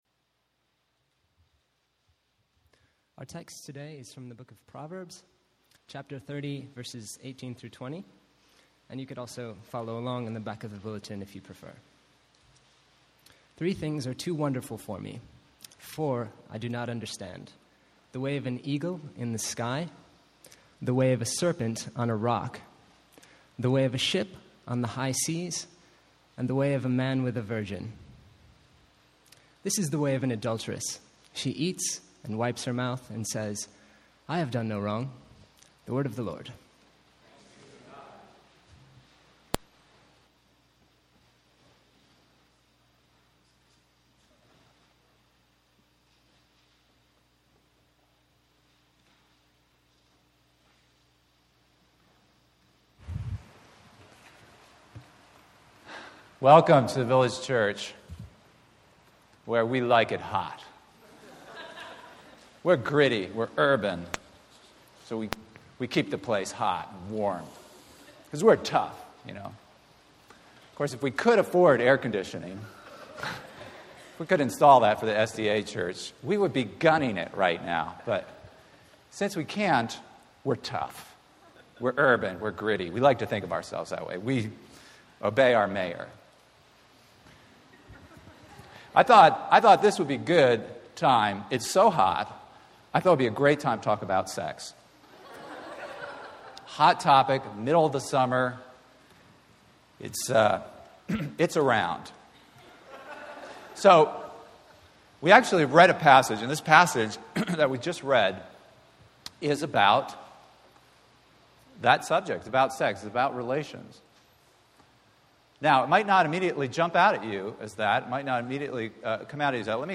For those who have a low view of physical intimacy, or who through their past can only see it as unclean, I hope that this sermon can serve as an antidote.